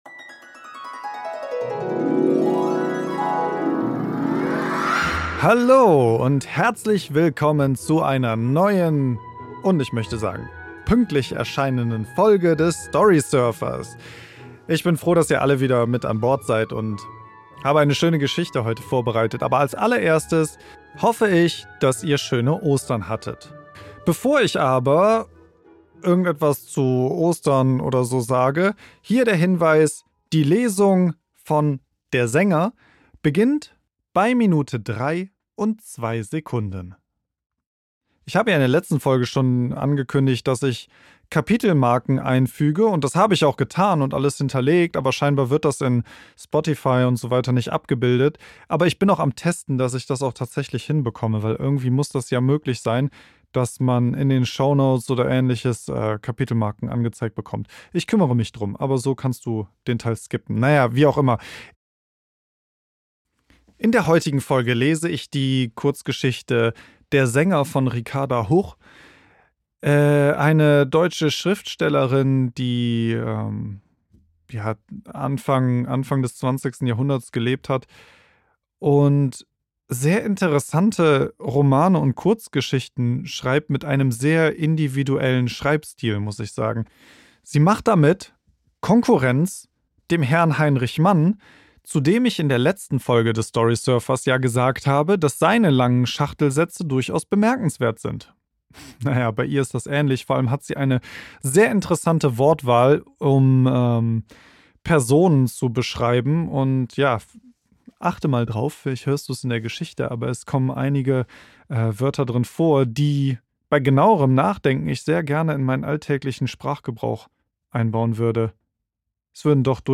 Ricarda Huch - Der Sänger ~ Storysurfer - Der Kurzgeschichten Hörbuch Podcast